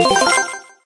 get_tickets_01.ogg